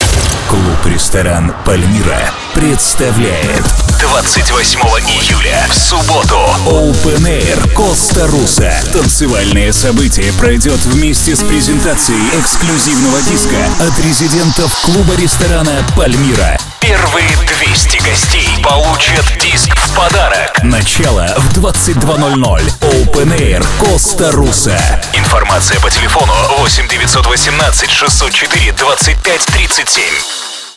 Информационный аудиоролик
В процессе изготовления аудиороликов обычно используются один или два диктора.